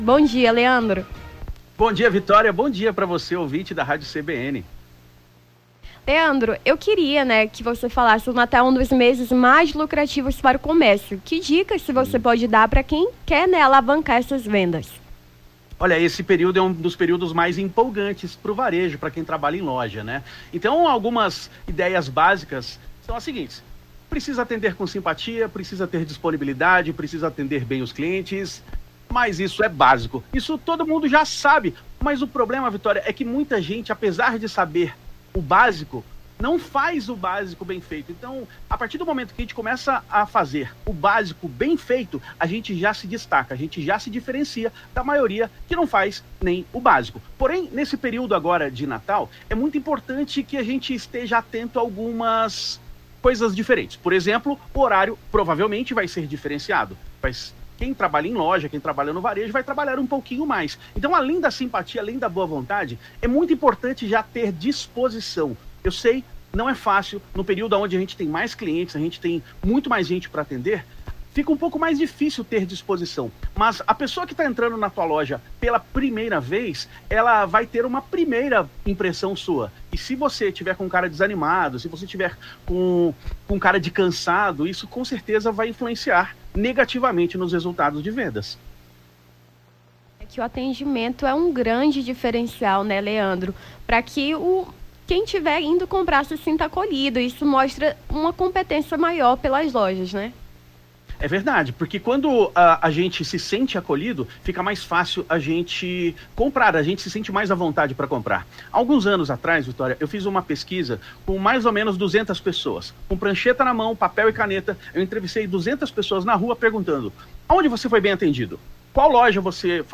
Nome do Artista - CENSURA - ENTREVISTA (COMERCIO NATAL) 06-12-23.mp3